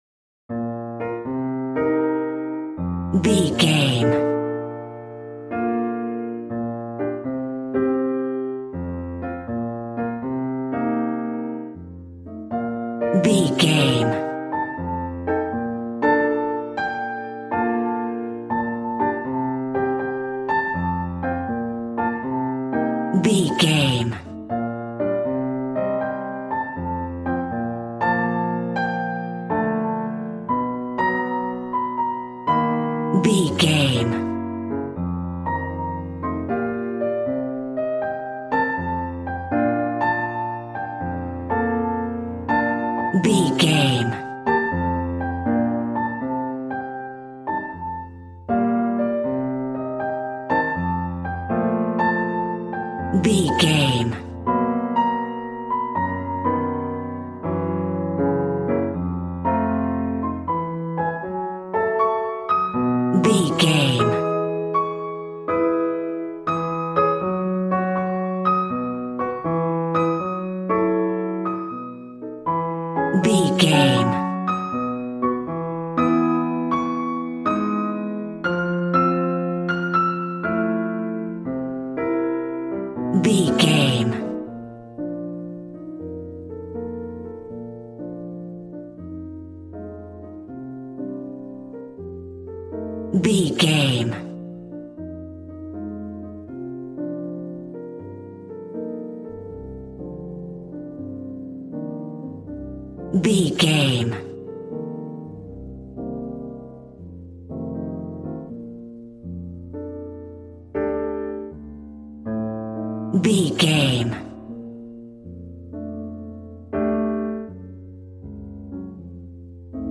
Smooth jazz piano mixed with jazz bass and cool jazz drums.,
Ionian/Major
B♭
piano
drums